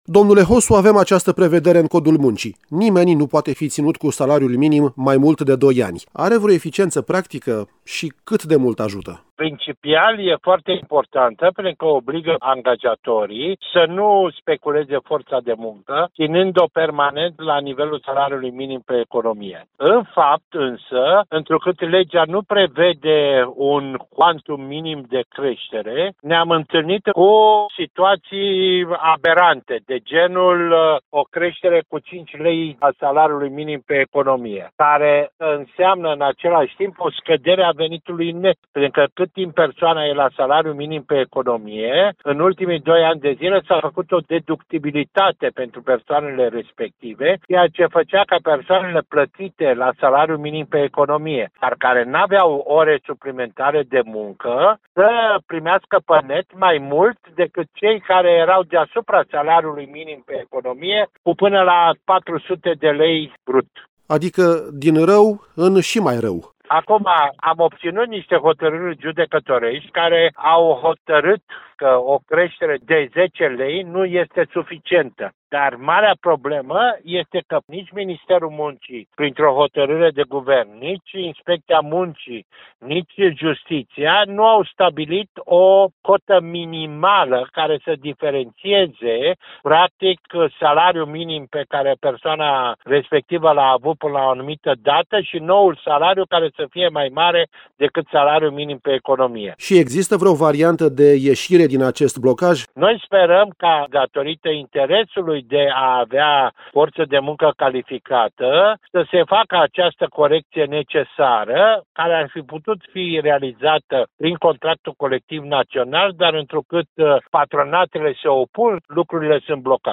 Răspunsurile, în interviul următor